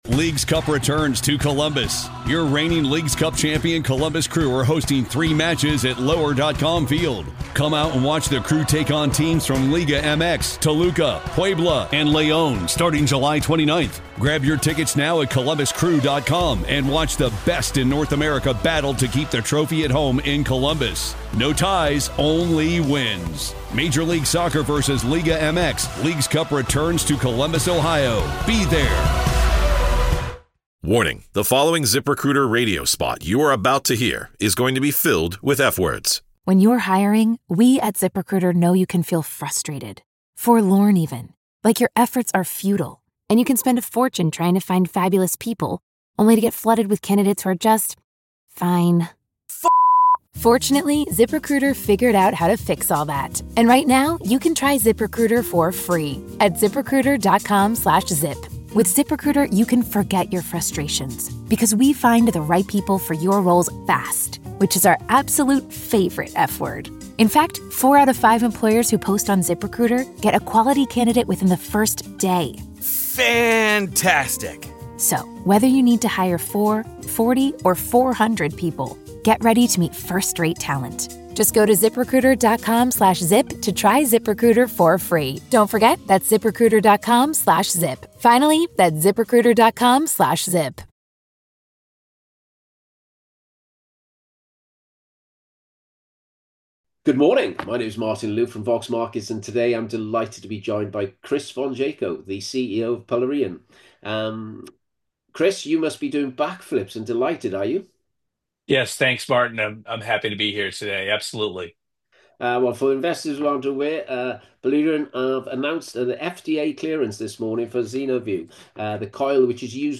Interview with CEO